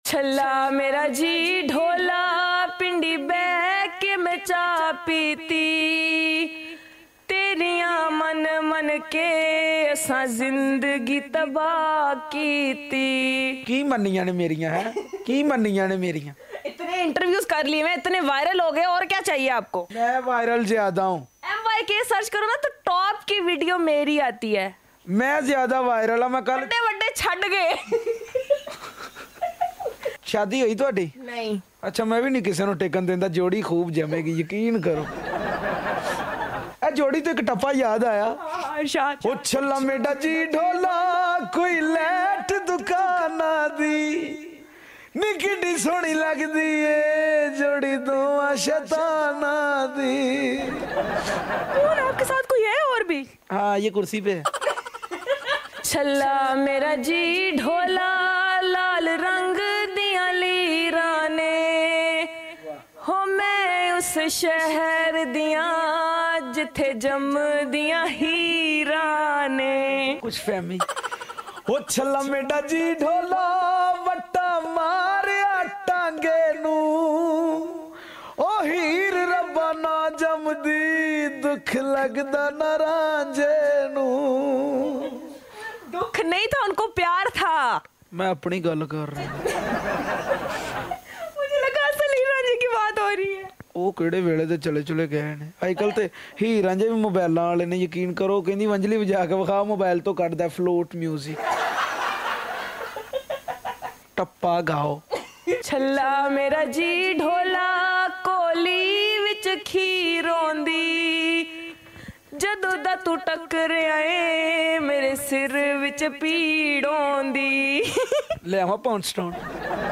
singing competition